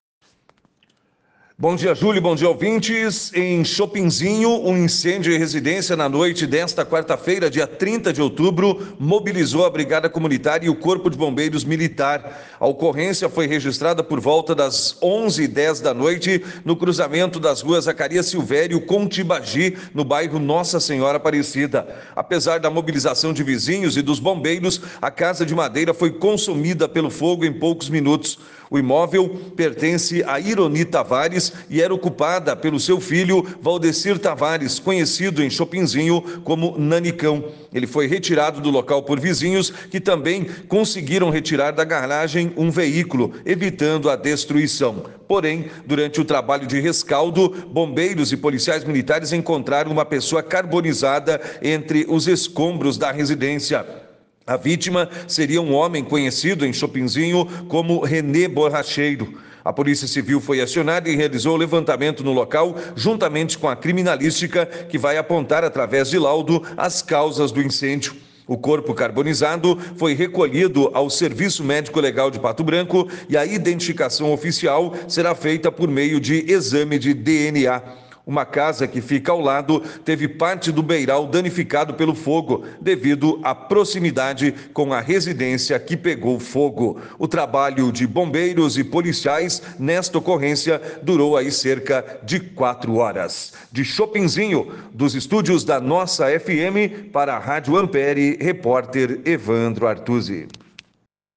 Ouça o boletim